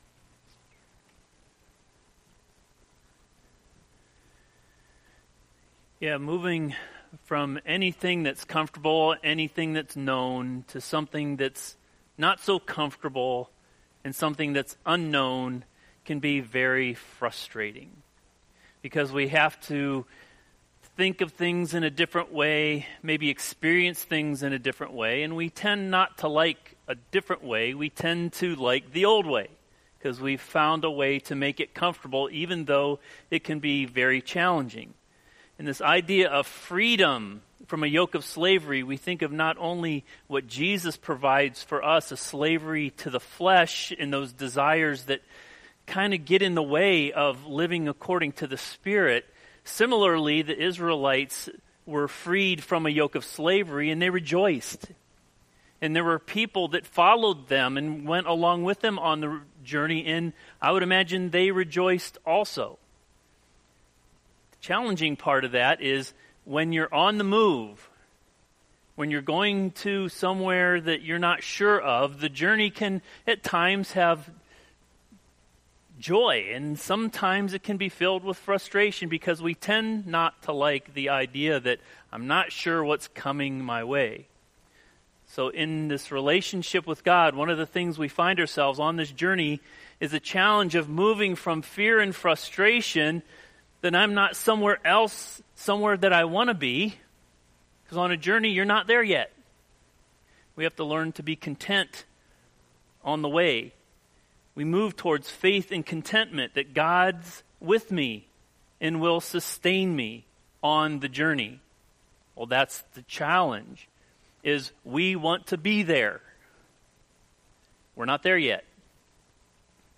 Sermon-May-3-2020.mp3